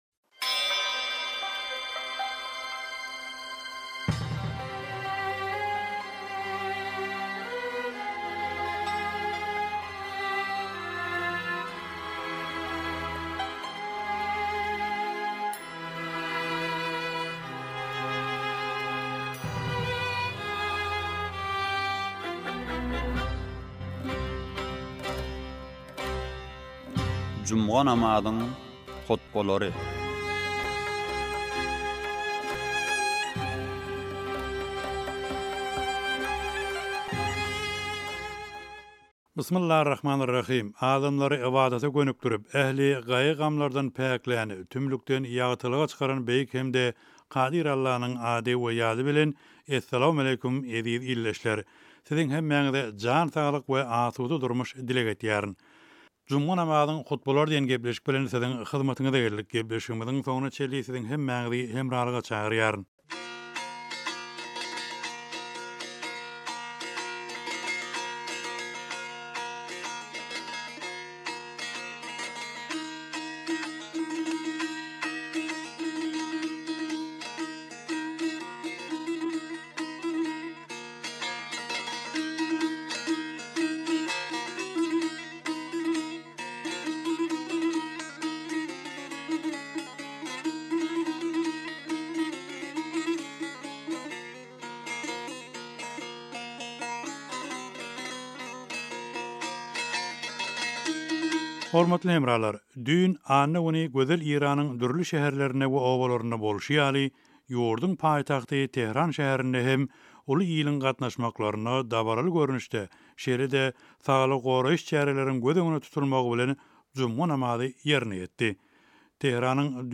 juma namazyň hutbalary
Tehraniň juma namazy